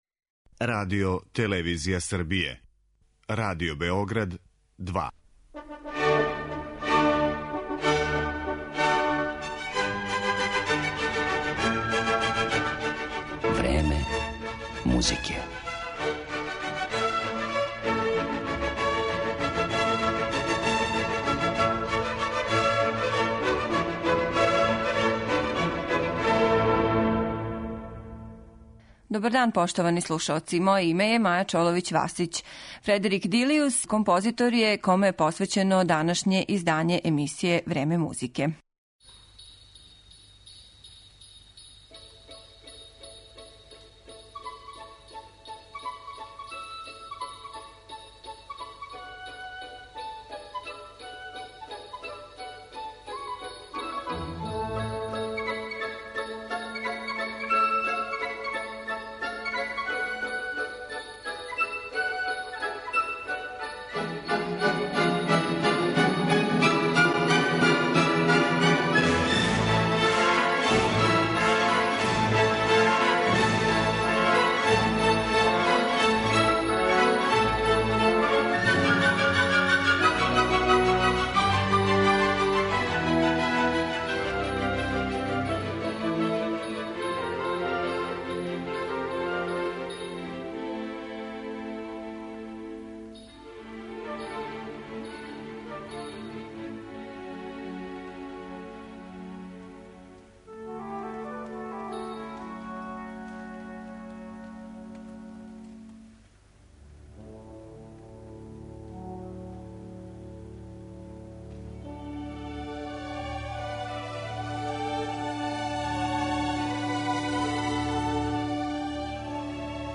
Последњи велики апостол романсе, емоције и лепоте у музици (како га је једном приликом назвао диригент Томас Бичем), написао је низ композиција препознатљивог, згуснутог оркестарског звука и богате мелодике.